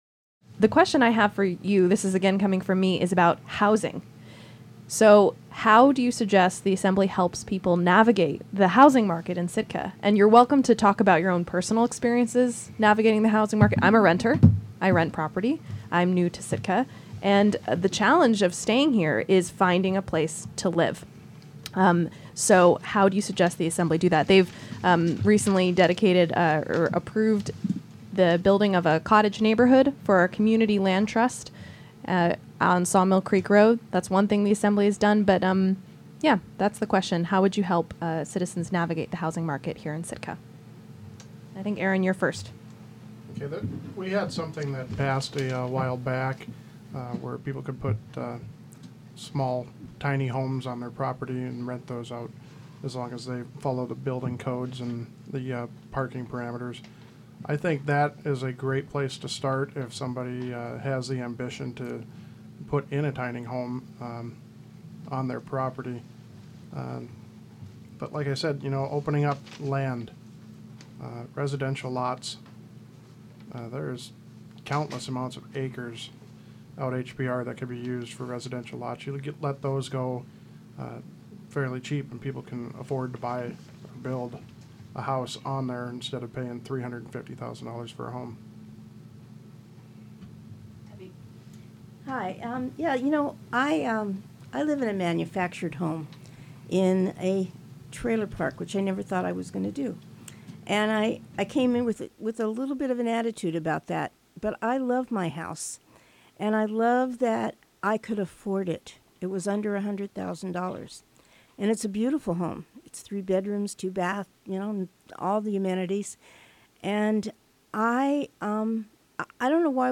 On Tuesday, September 20th, the five candidates for Sitka Assembly came into our studio to discuss their ideas and positions in the lead-up to the October 7th election.